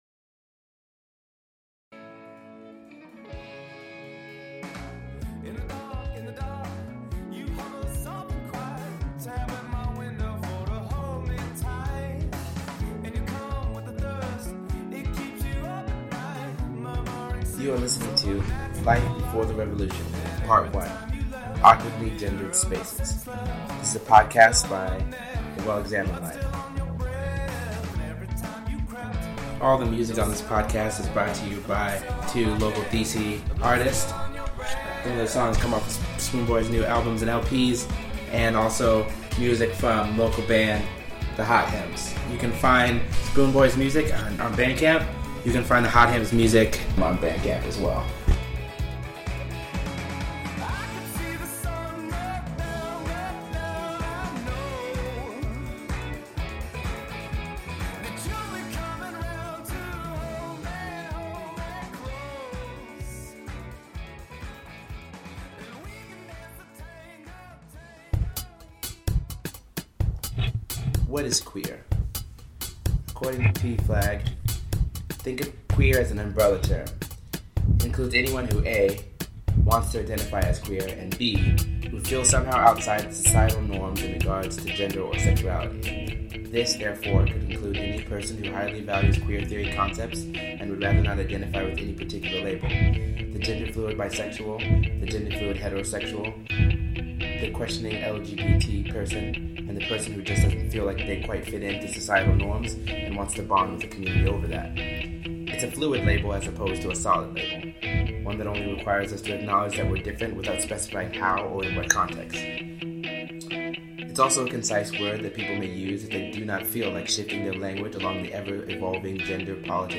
I will probably write more about this play later and will post the script soon but for now…here is my first ever radio theater comedy podcast!